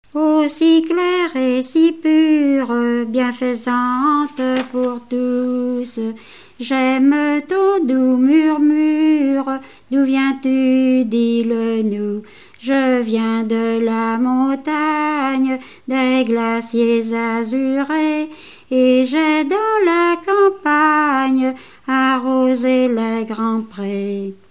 enfantine : lettrée d'école
Genre strophique
Pièce musicale inédite